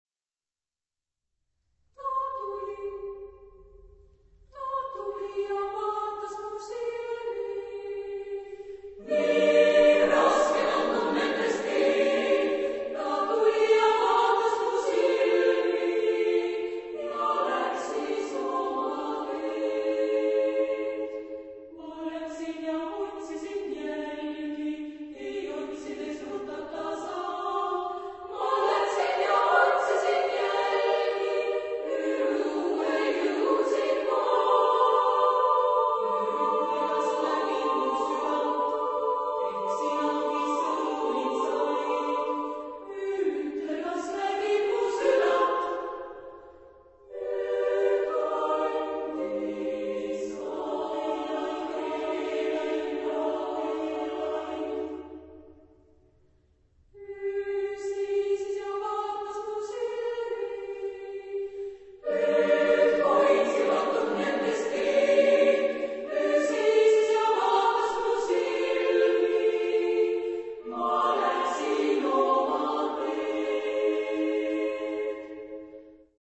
Genre-Style-Forme : Profane ; Pièce vocale
Type de choeur : SSAA  (4 voix égales de femmes )